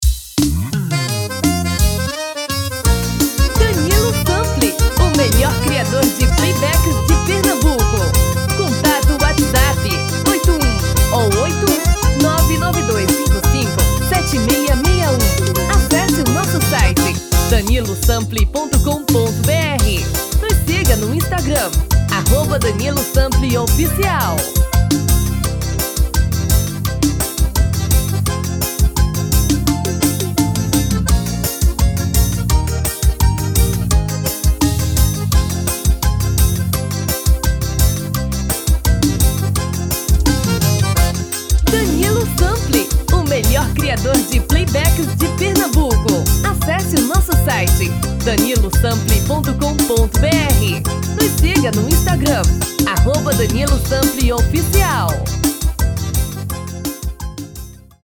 DEMO 1: tom original DEMO 2: tom feminino